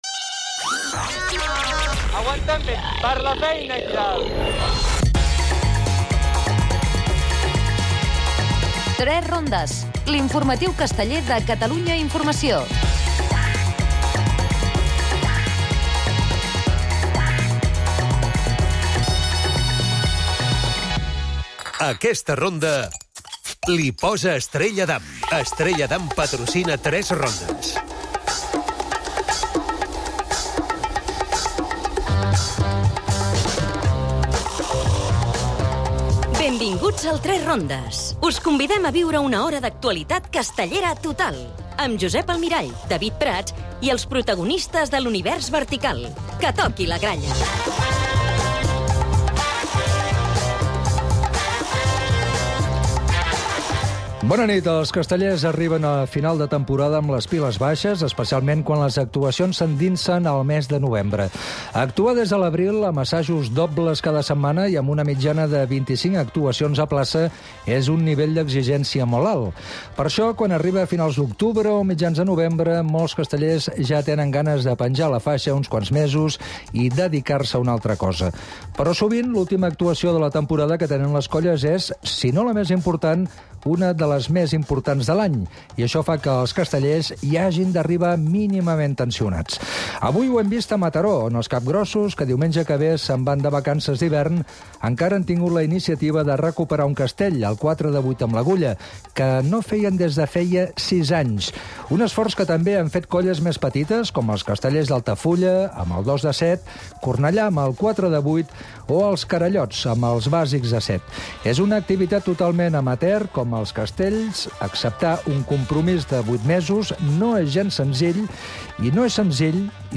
presenten l'informatiu casteller en tres rondes, que inclou anlisi, reflexi i valoraci de l'actualitat castellera.